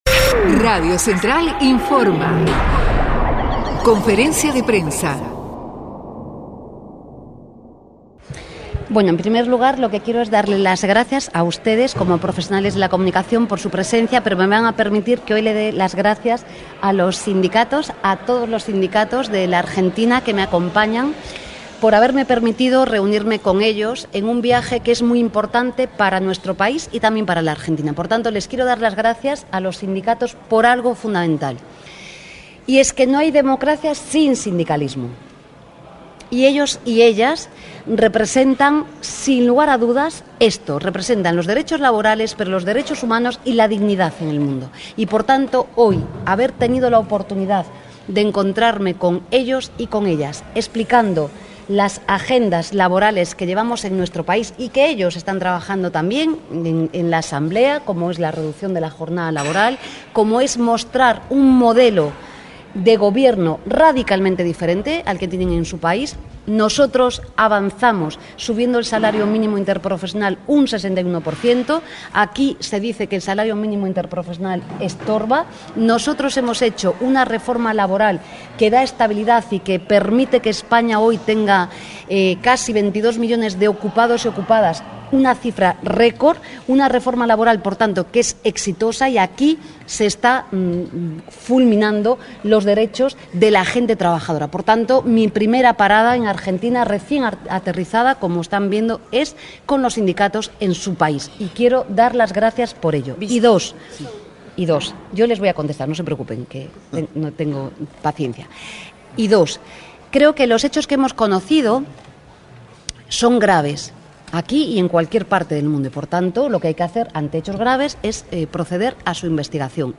La vicepresidenta y ministra de Trabajo y Economía Social de España, Yolanda Díaz, se reunió con representantes de las tres centrales sindicales argentinas en la sede nacional de la Confederación de Trabajadores de la Educación de la República Argentina